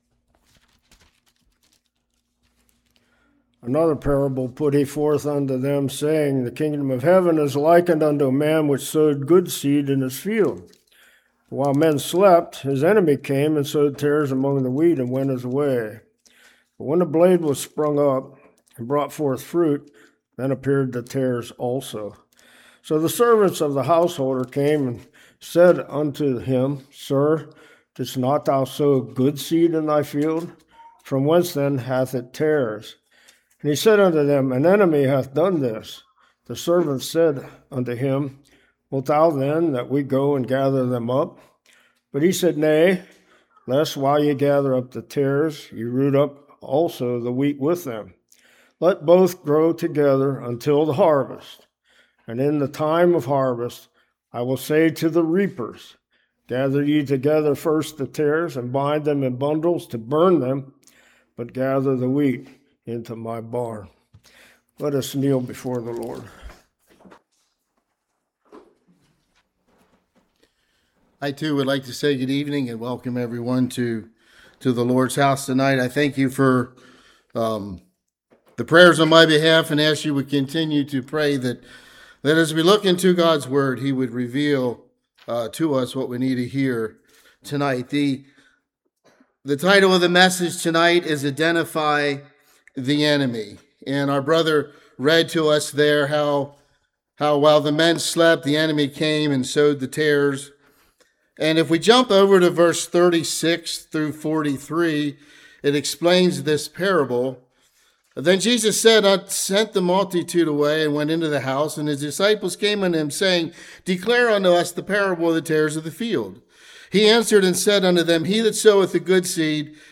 Matthew 13:24-30 Service Type: Revival Satan